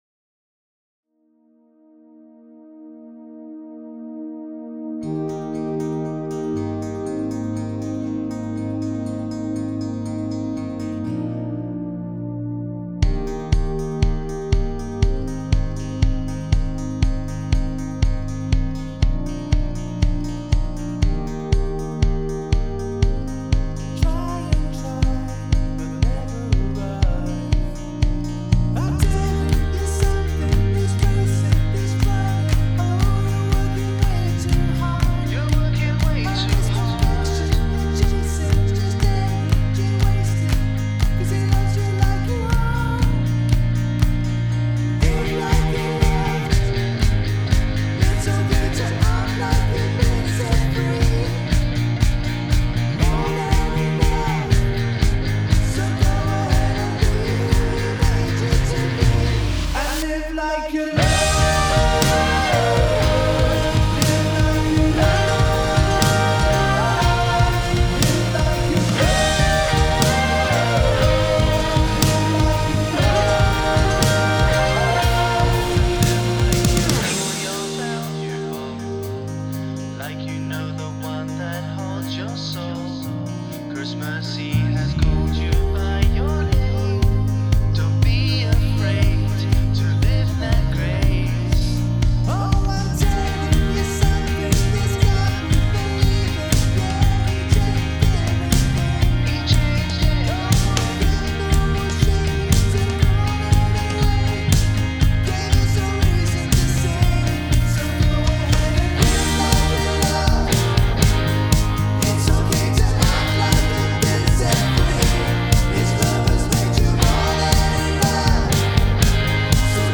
Key: E BPM: 120 Time sig: 4/4 Duration:  Size: 8.9MB
Contemporary Pop Rock Worship